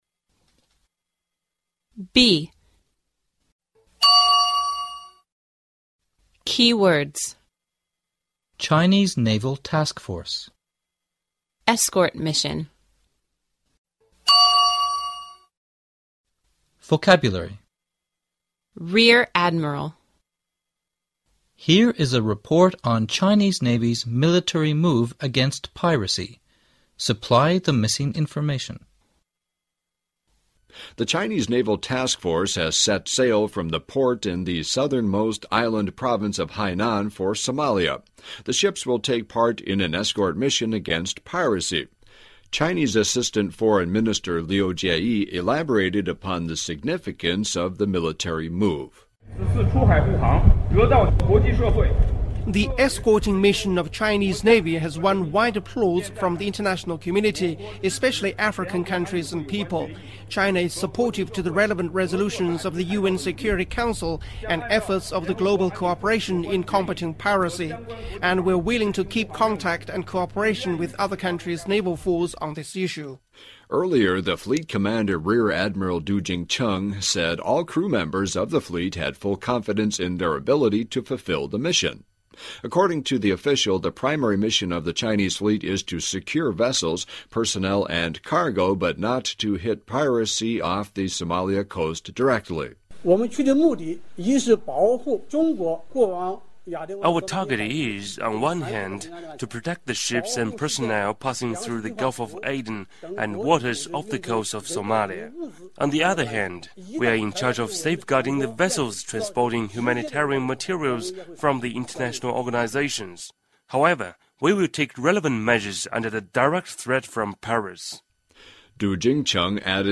Here is a report on Chinese navy's military move against piracy.